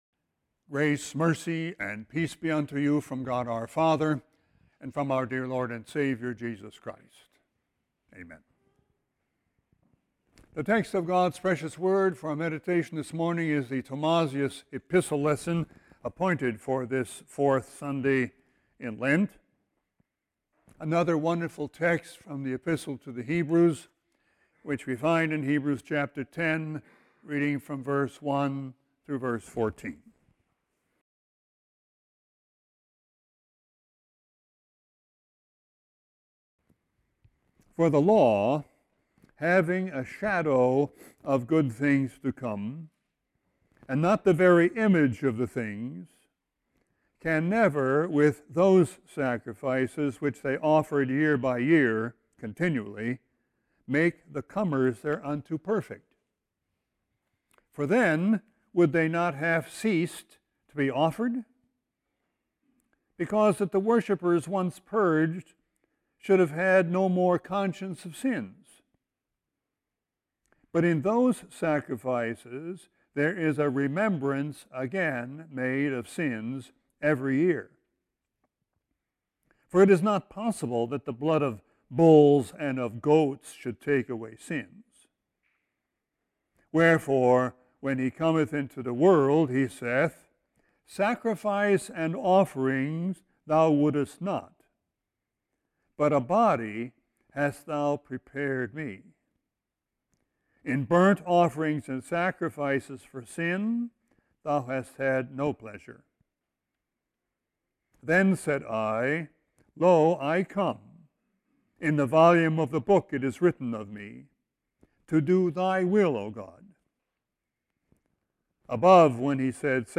Sermon 3-11-18.mp3